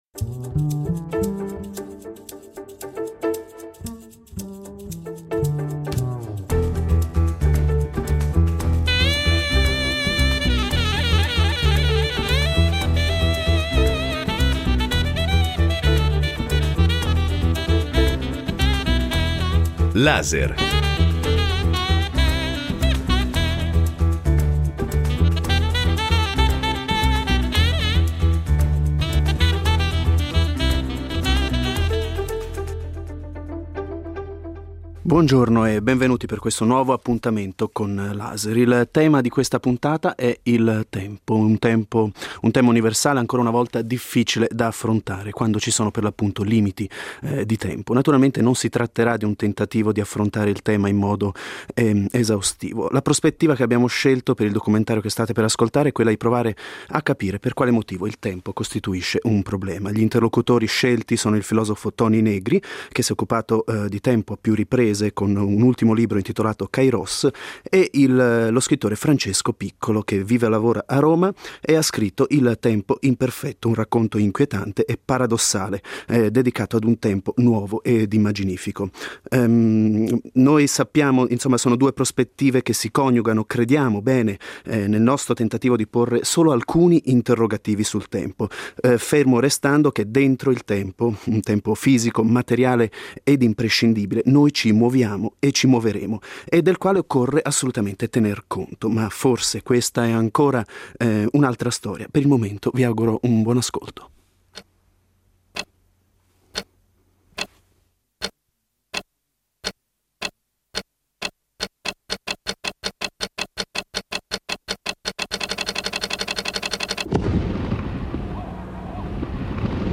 Laser ripropone una intervista realizzata al filosofo e attivista Toni Negri (1933 – 2023) realizzata nel 2001 e dedicata al tema del tempo.
Nel documentario anche un contributo dello scrittore Francesco Piccolo.